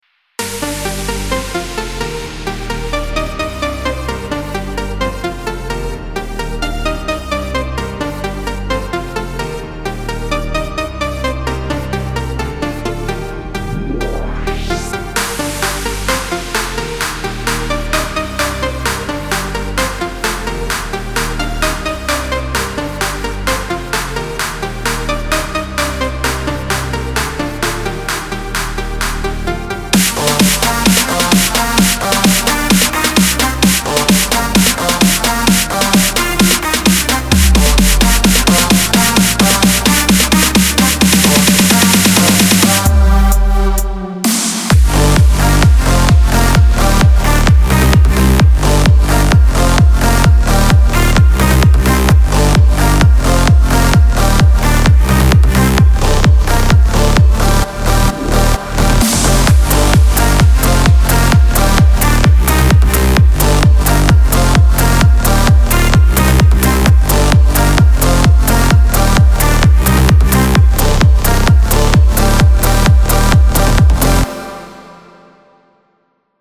בנוי טוב מלודיה שחוזרת על עצמה ומשעממת